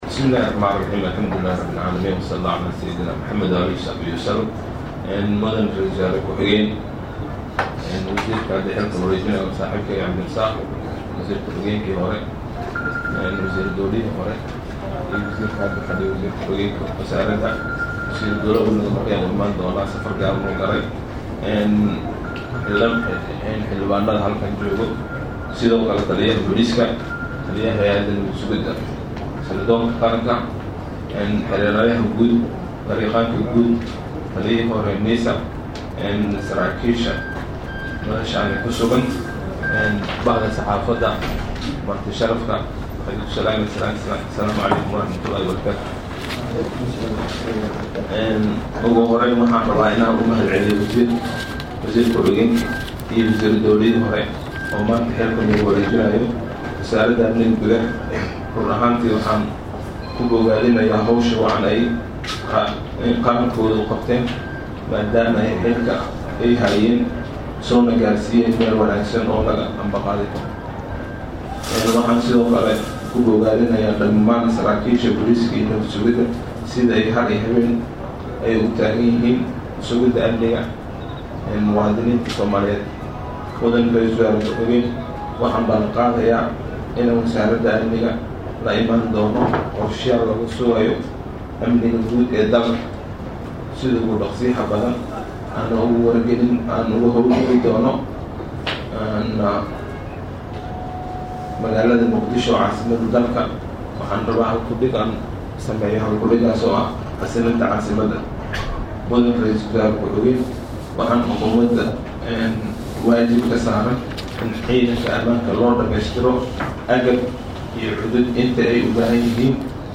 Hoos ka dhegeyso hadalka wasiirka cusub
wasiirka-cusub-maxamed-Abuukar-Islow-.mp3